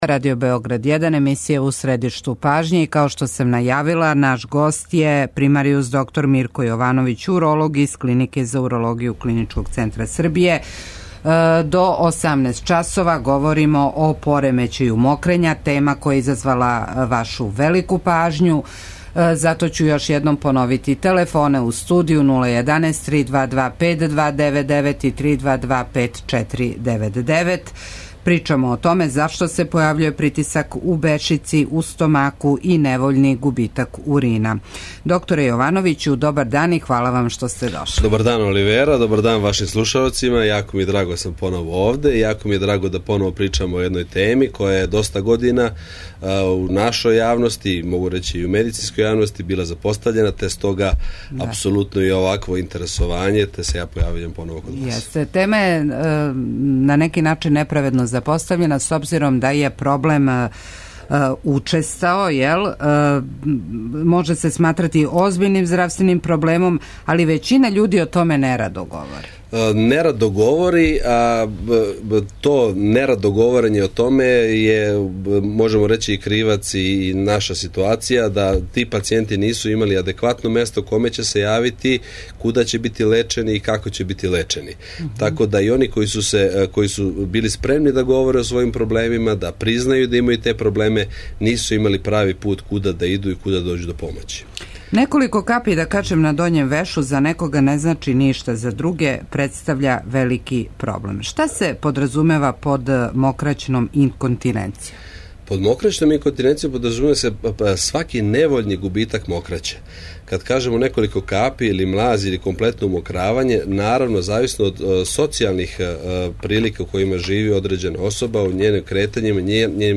У здравственој трибини емисије У средишту пажње говоримо о поремећају мокрења. Зашто се појављује притисак у стомаку и невољни губитак урина?